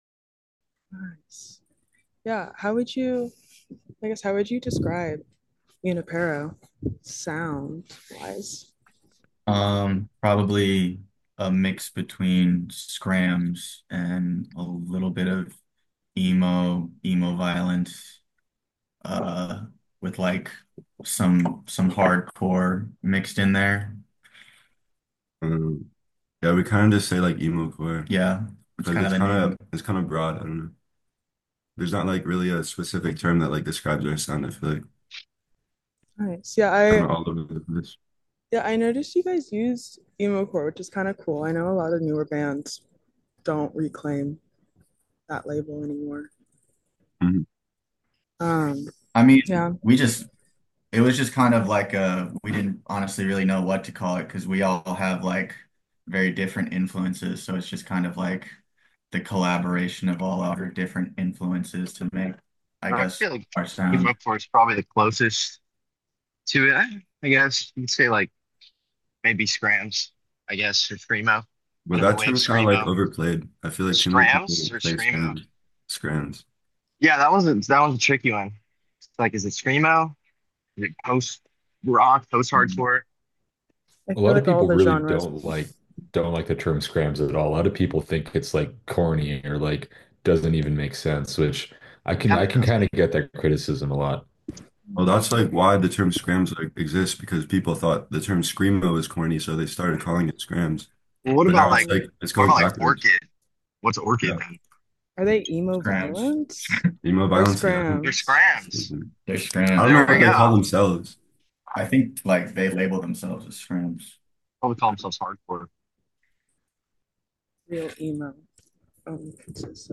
The Sound Of San Francisco Emocore: An Interview With Junipero - Events | KZSC Santa Cruz